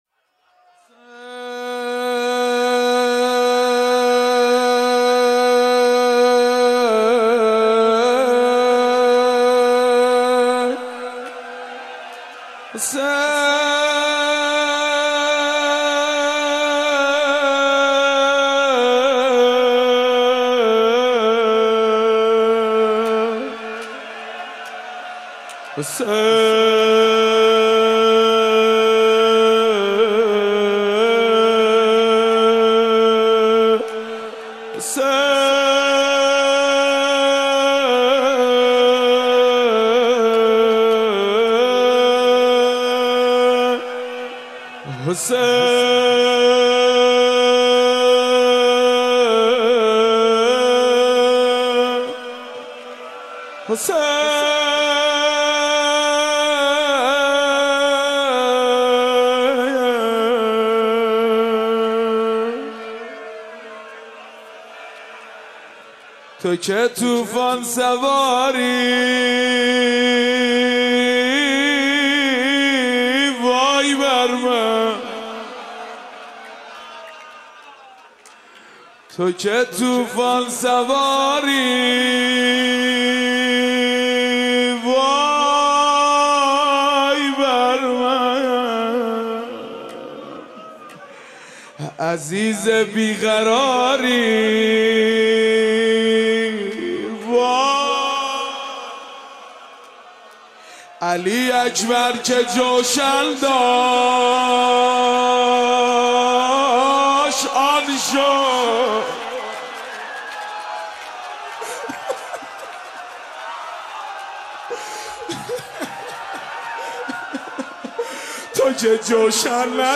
01-Rozeh-1.mp3